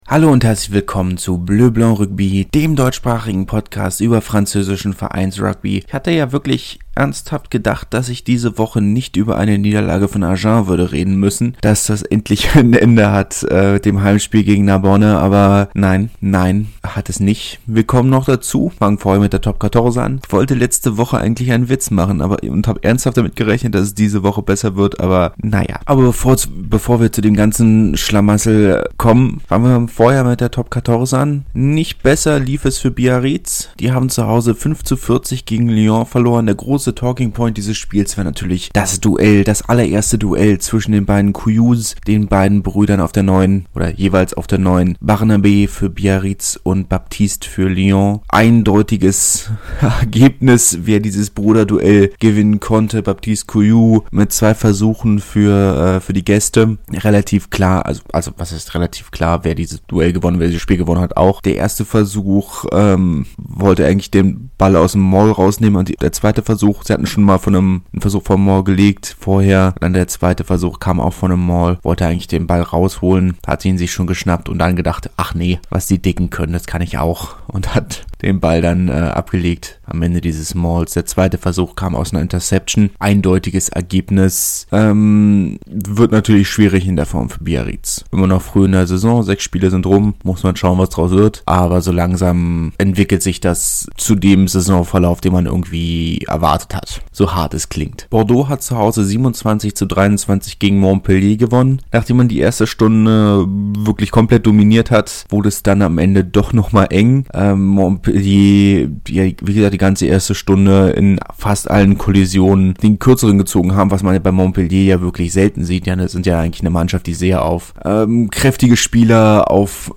Vorwarnung: Ich bin etwas verschnupft und an ein bis zwei Stellen nicht ganz so deutlich zu verstehen wie ich es gern wäre.